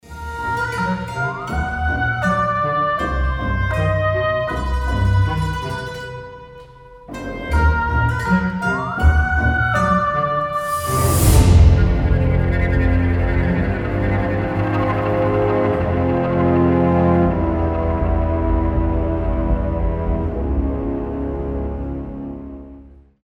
инструментальные
жуткие , мистические
оркестр
без слов